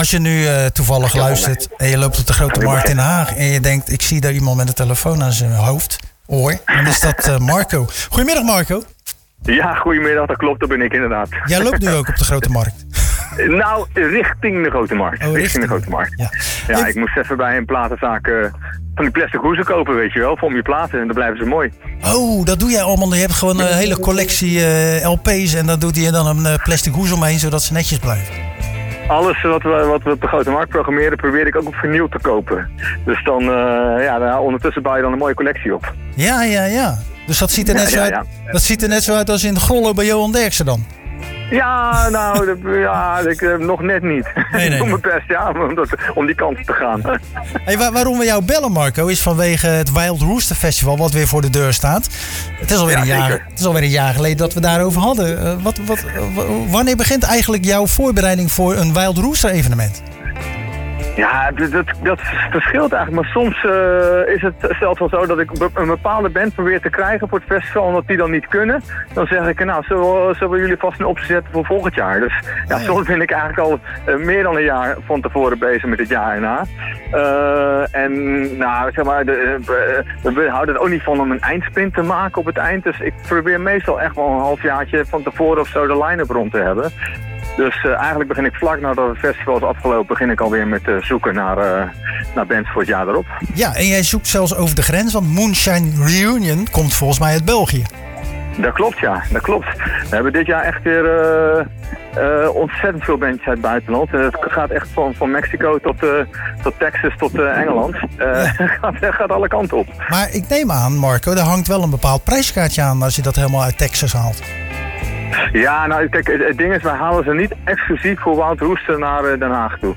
Het laatste weekend van augustus staat traditioneel het Wild Rooster Festival op de agenda. Tijdens het programma Zwaardvis belde we